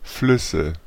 Ääntäminen
Ääntäminen France: IPA: [œ̃ flœv] Tuntematon aksentti: IPA: /flœv/ Haettu sana löytyi näillä lähdekielillä: ranska Käännös Konteksti Ääninäyte Substantiivit 1.